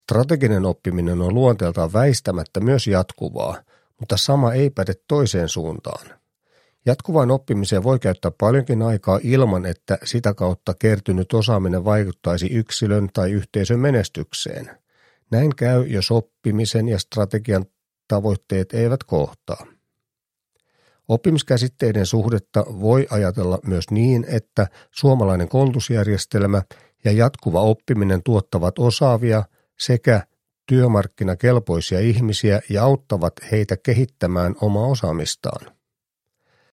Strateginen oppiminen – Ljudbok